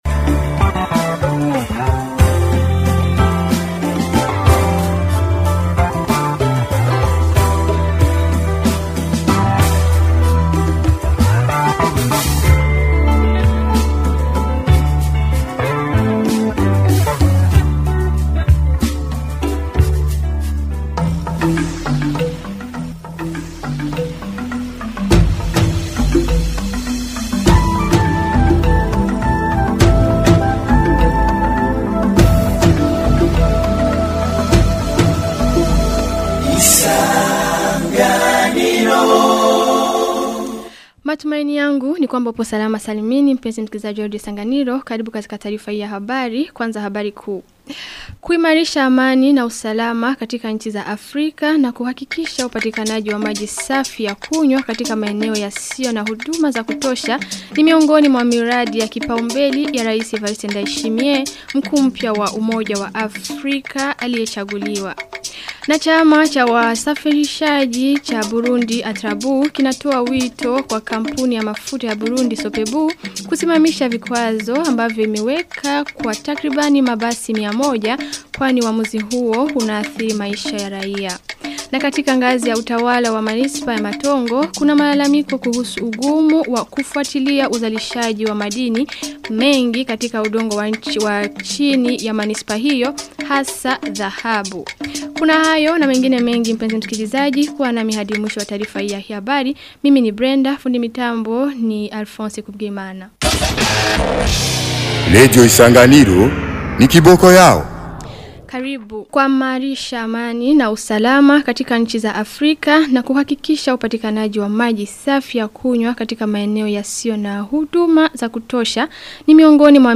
Taarifa ya habari ya tarehe 16 Februari 2026